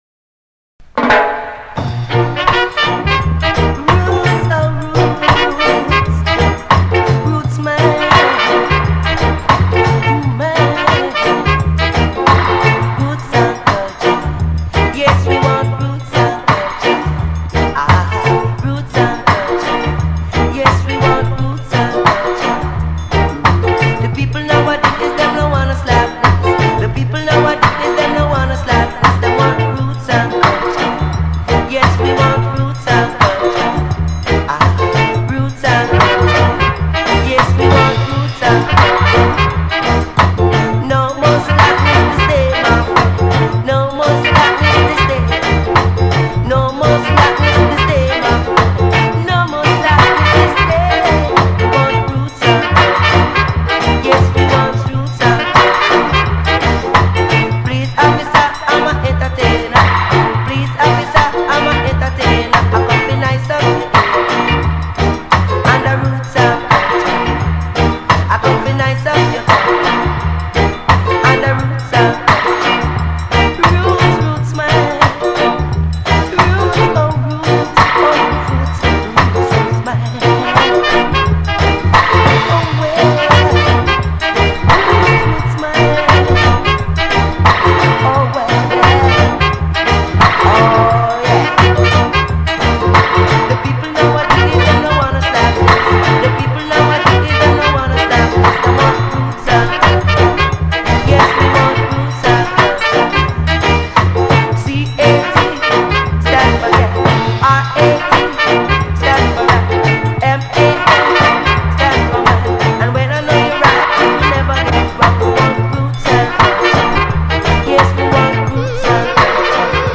ROOTS & REALITY CULTURE FROM FOUNDATION SELECTION !!